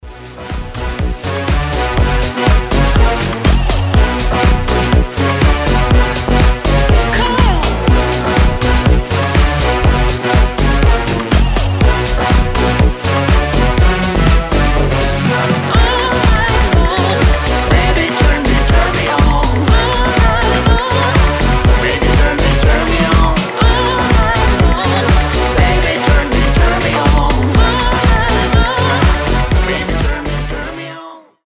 • Качество: 320, Stereo
Electronic
Dance Pop
чувственные
house